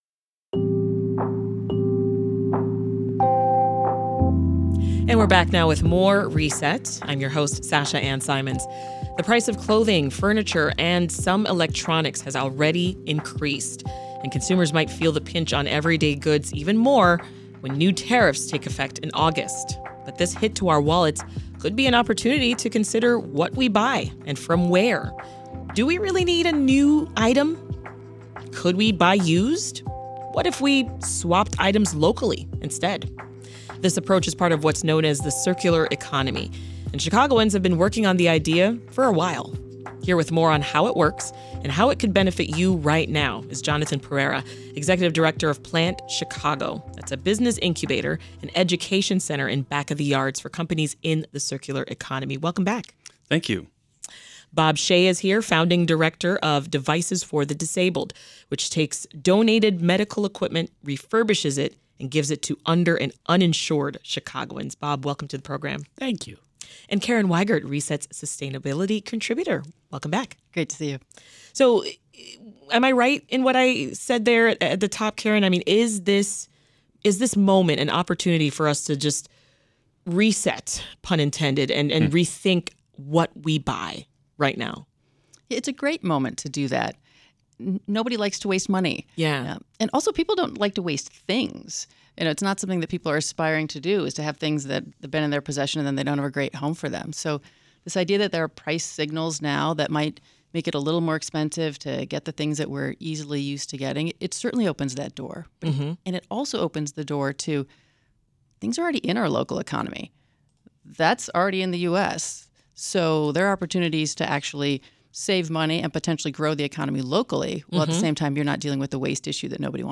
Listen to WBEZ Reset Interview